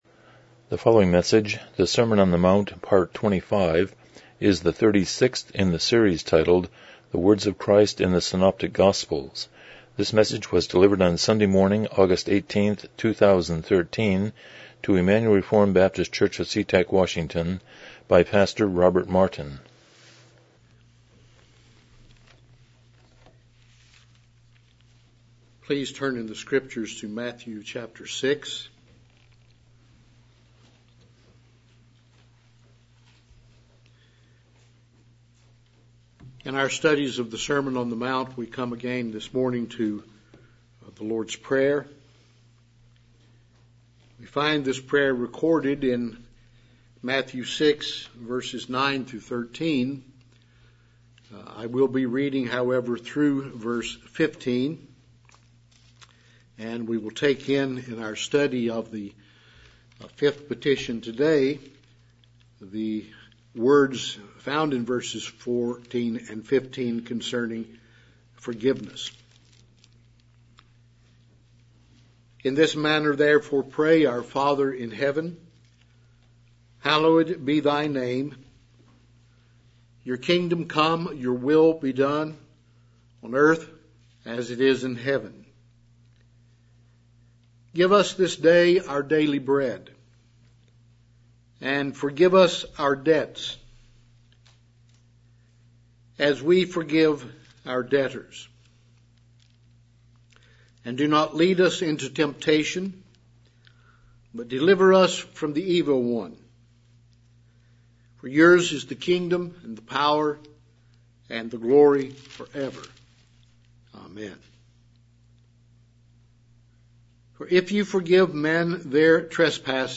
Passage: Matthew 6:7-15 Service Type: Morning Worship